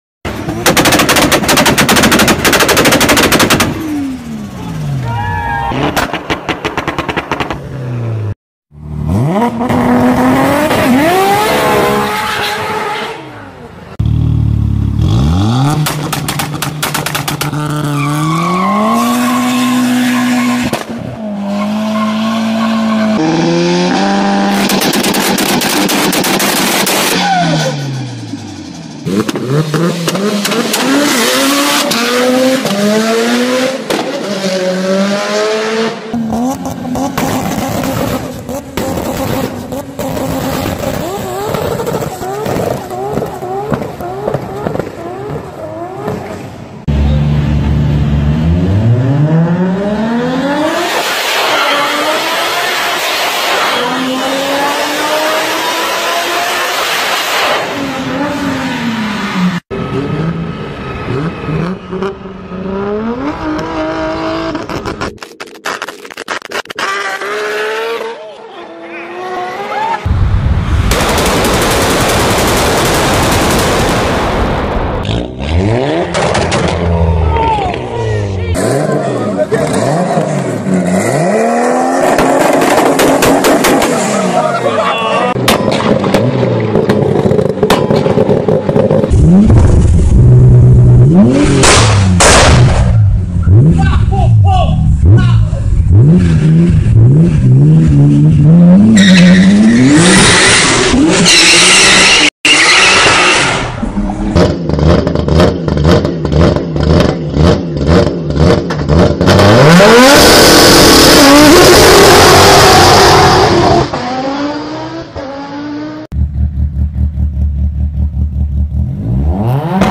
دانلود آهنگ صدای گاز دادن ماشین 1 از افکت صوتی حمل و نقل
جلوه های صوتی
برچسب: دانلود آهنگ های افکت صوتی حمل و نقل دانلود آلبوم صدای گاز دادن ماشین – توربو و انواع مختلف از افکت صوتی حمل و نقل